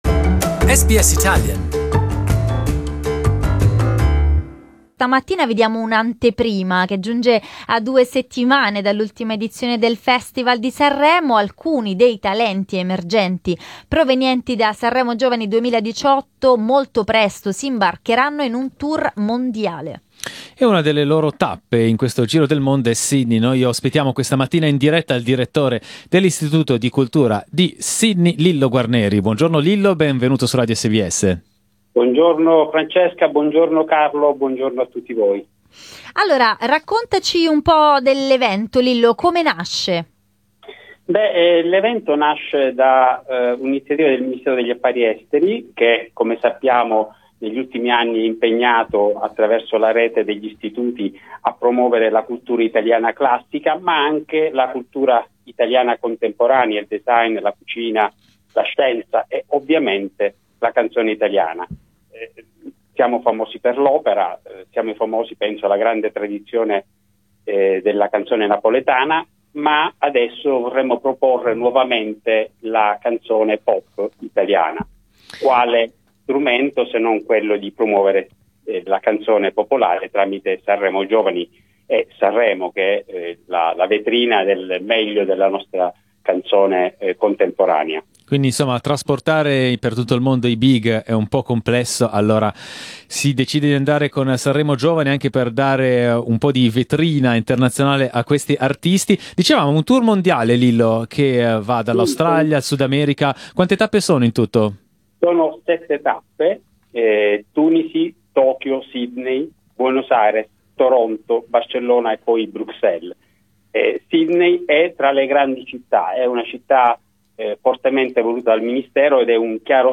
ha parlato dell'evento ai microfoni di SBS Italian.